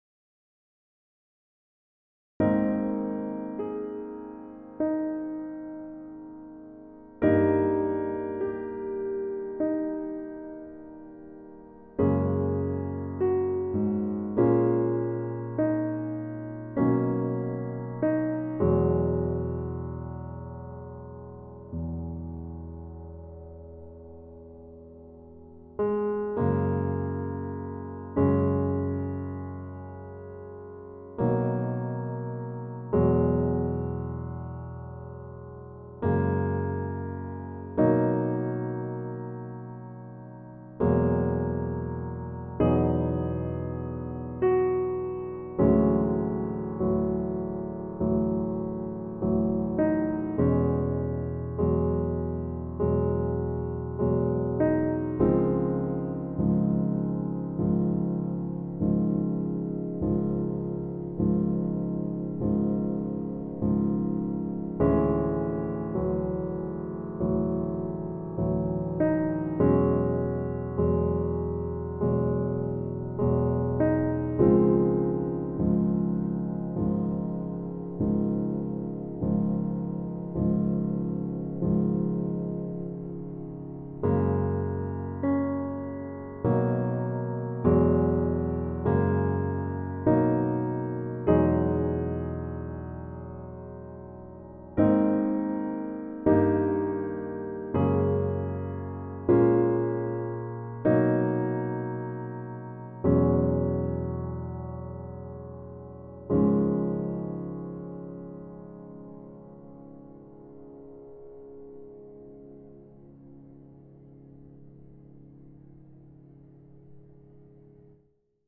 時にシンプルで 時に複雑なひびき 重なりあう音色の変化 ジャジーなテンション 人間らしいアナログな間